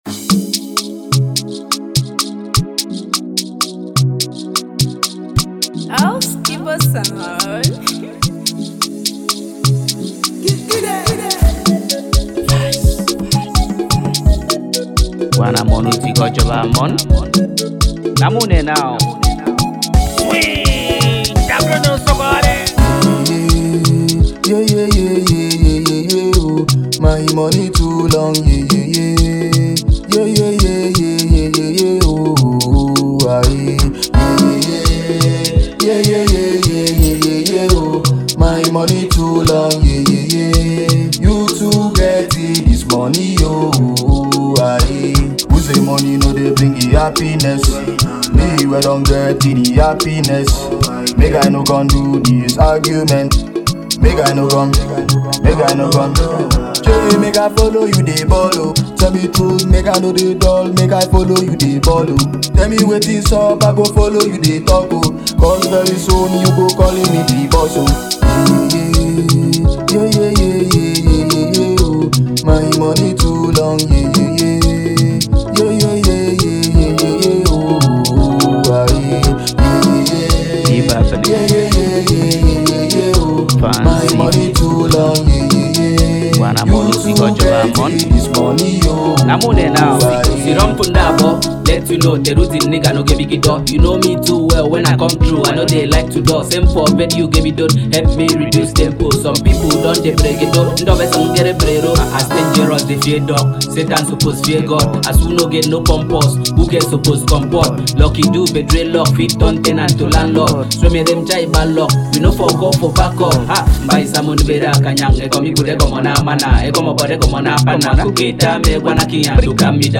afro-gyration singer
a genre best described as Gyration-Rap.